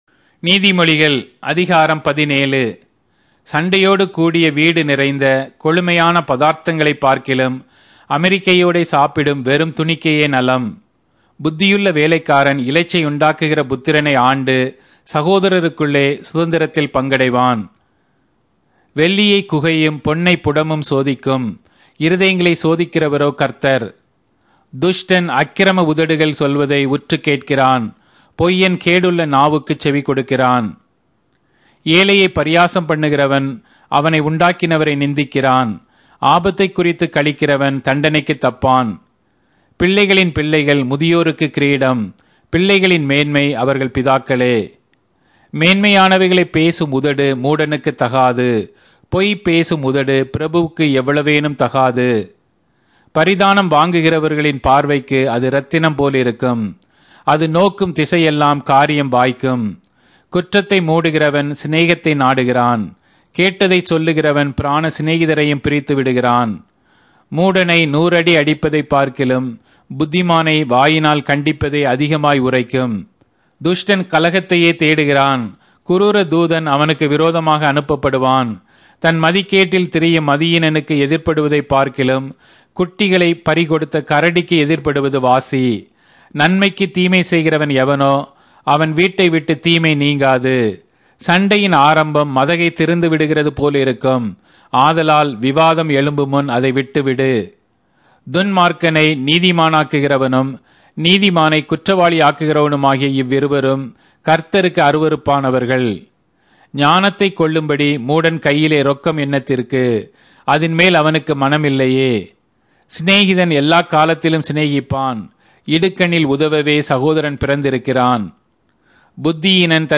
Tamil Audio Bible - Proverbs 13 in Knv bible version